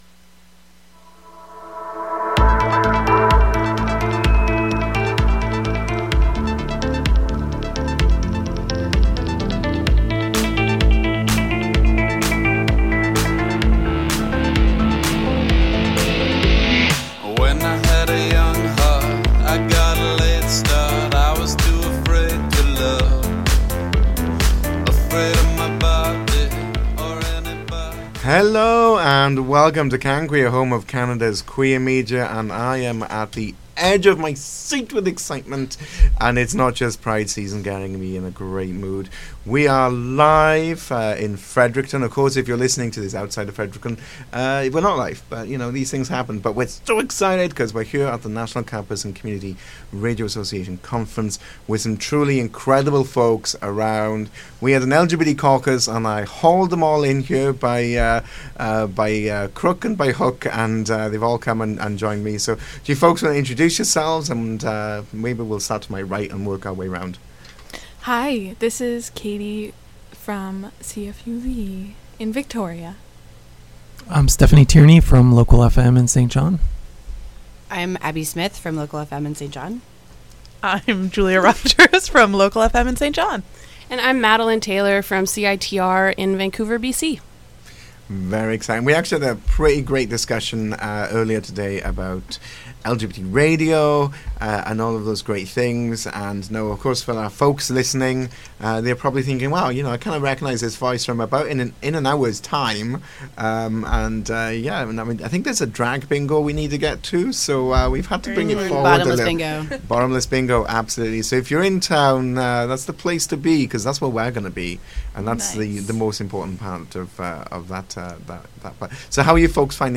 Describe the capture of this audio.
is Live in Fredericton, with special guests from across Canada!